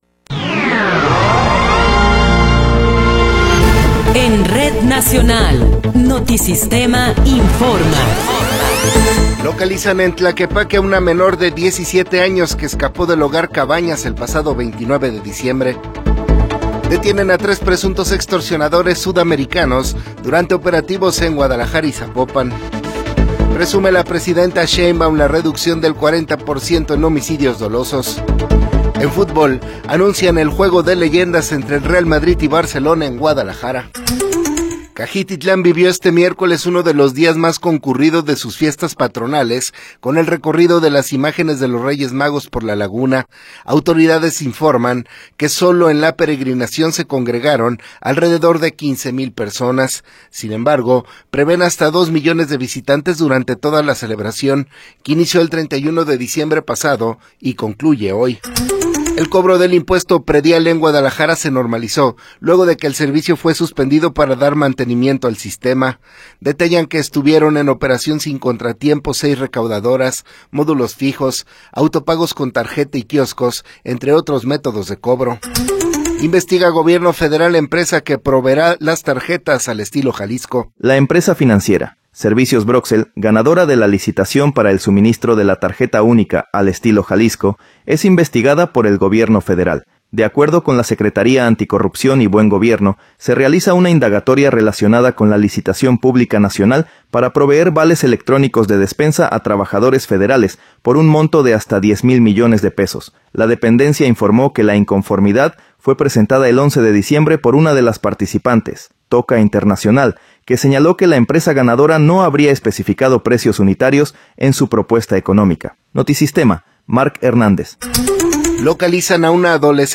Noticiero 9 hrs. – 8 de Enero de 2026
Resumen informativo Notisistema, la mejor y más completa información cada hora en la hora.